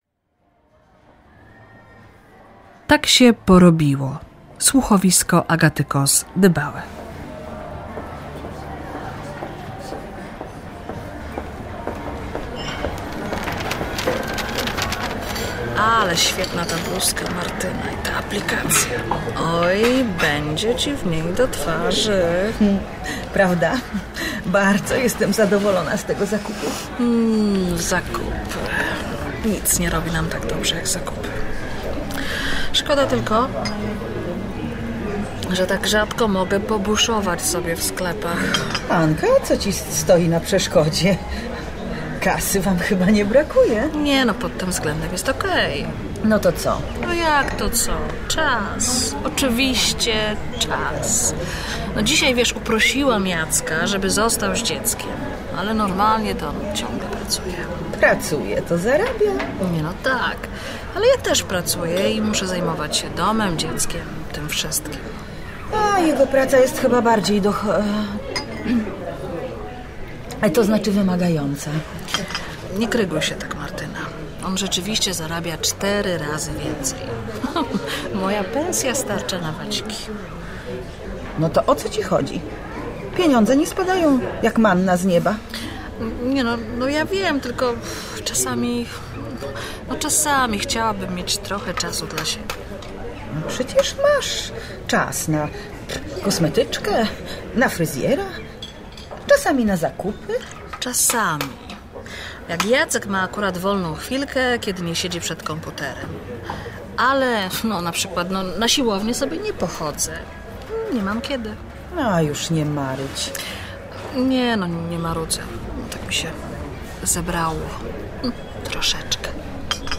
Słuchowisko Agaty Koss- Dybały o zmianie ról społecznych kobiet i mężczyzn.